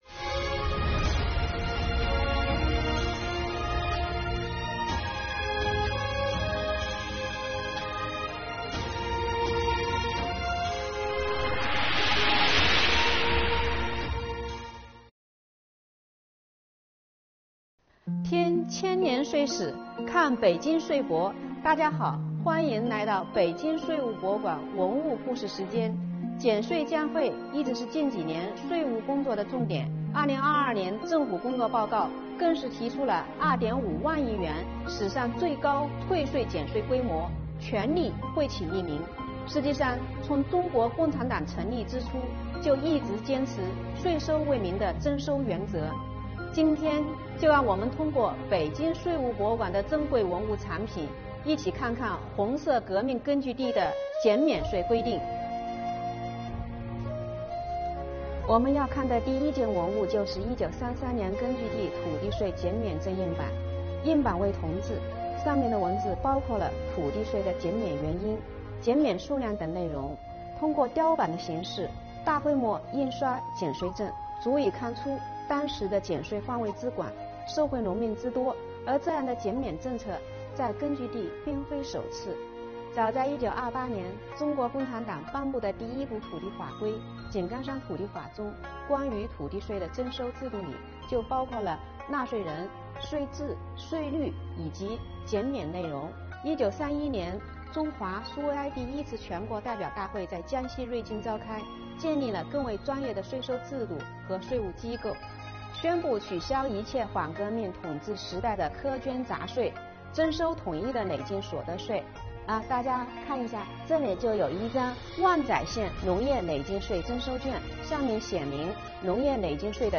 北京税务博物馆文物故事讲述人为大家介绍红色革命根据地那些税收减免的规定↓